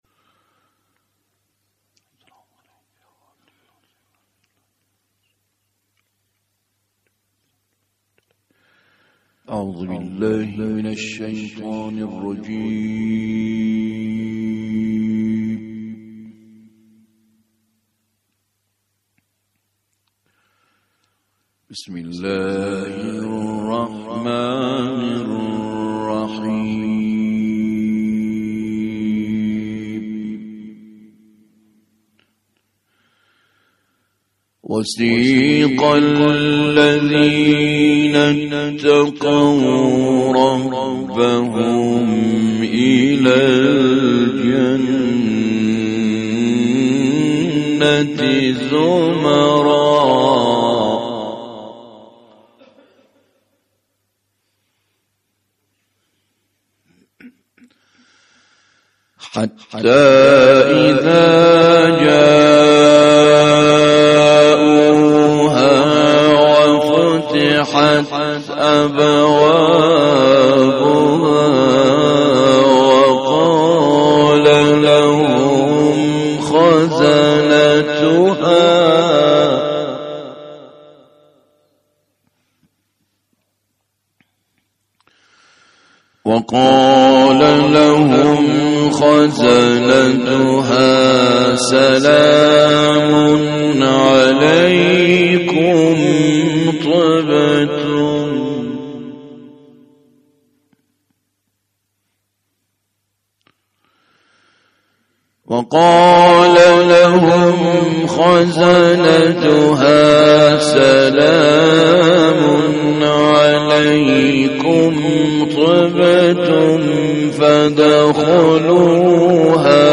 تلاوت
قاری بین‌المللی در دهه اول ماه محرم با حضور در حسینیه ثارالله(ع) شمیران